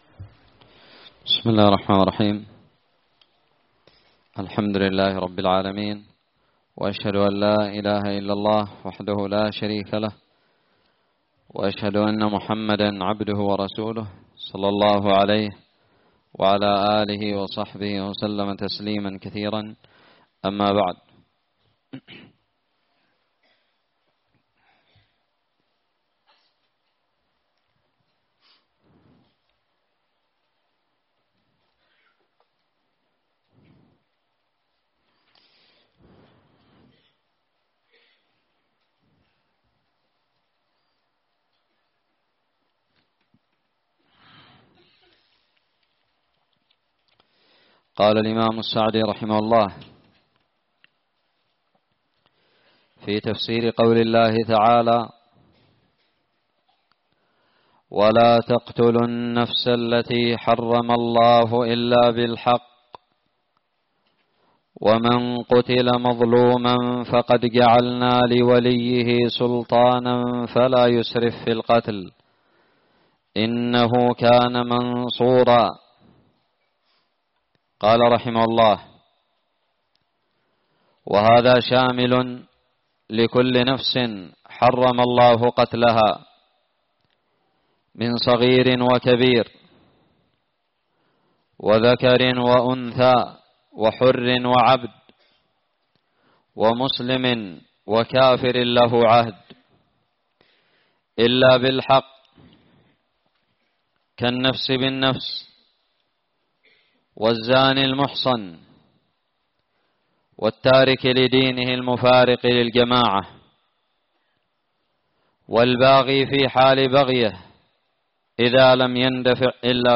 الدرس التاسع من تفسير سورة الإسراء
ألقيت بدار الحديث السلفية للعلوم الشرعية بالضالع